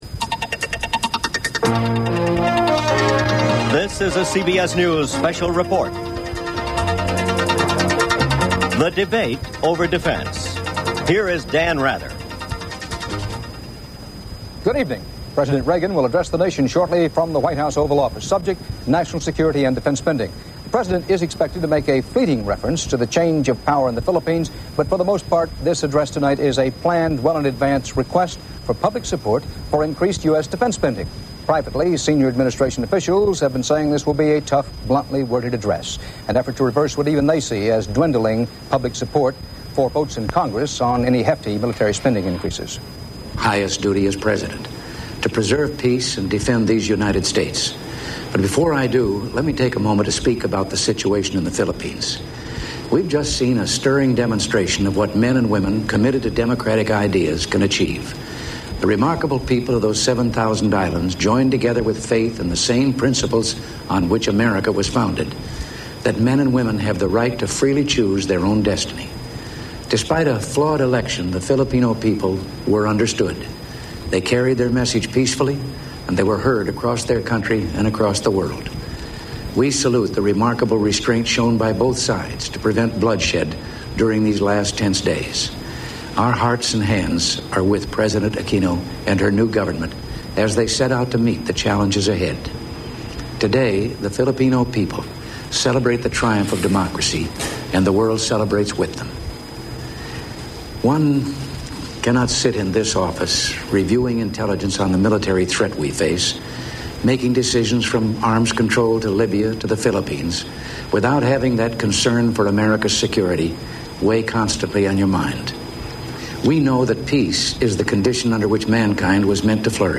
Ronald Reagan appeals directly to U.S. citizens to support increased defense spending. He also speaks briefly about the revolution in the Philippines. U.S. House of Representatives Majority Leader Jim Wright calls for a cost-accountable Pentagon. A CBS special report, with reporting by Dan Rather.